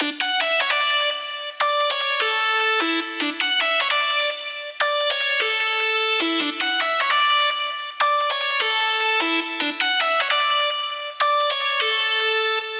150BPM Lead 04 Dmaj.wav